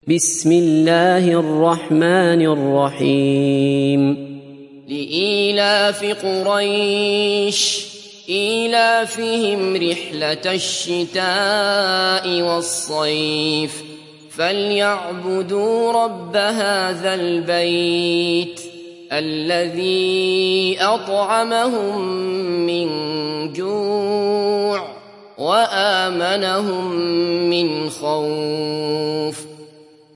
Kureyş Suresi mp3 İndir Abdullah Basfar (Riwayat Hafs)